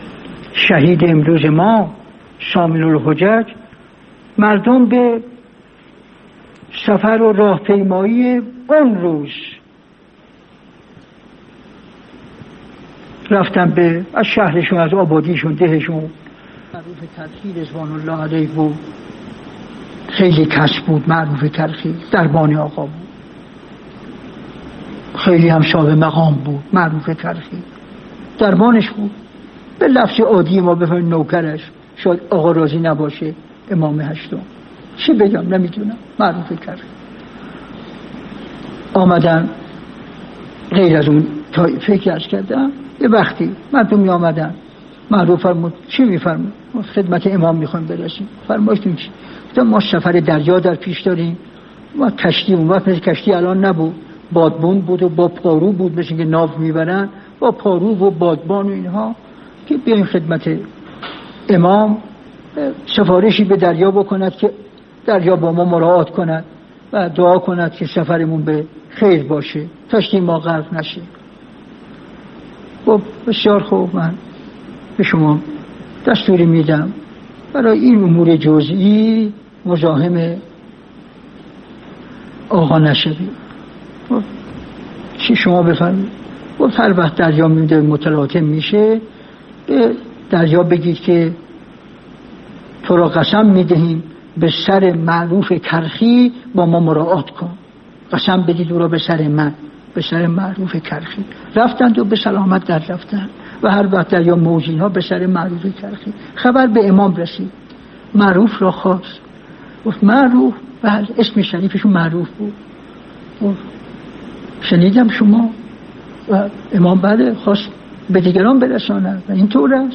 روضه امام رضا علیه السلام توسط علامه حسن زاده آملی(ره)
روضه علامه حسن زاده آملی(ره) در روز شهادت آقا امام ثامن الحجج حضرت علی ابن موسی الرضا علیه السلام منبع: AGLOESHG@